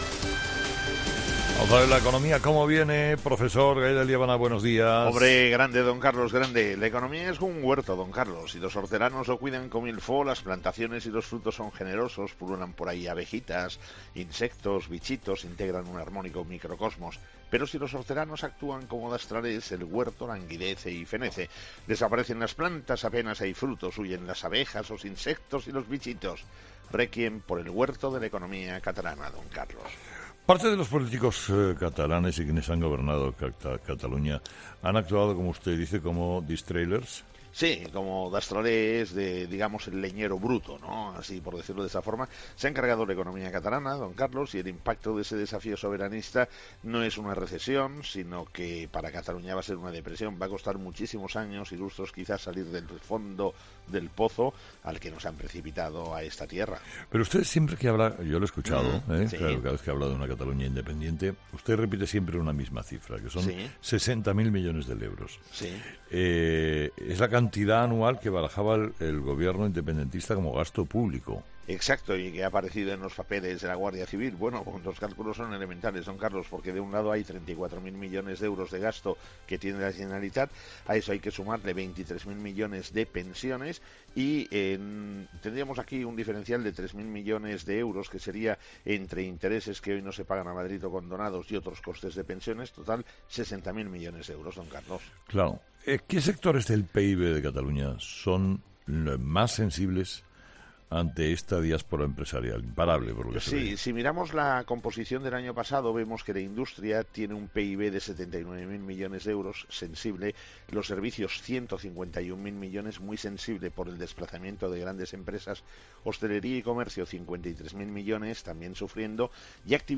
Las consecuencias del desafío soberanista catalán, en la actualidad económica del profesor Gay de Liébana en 'Herrera en COPE'.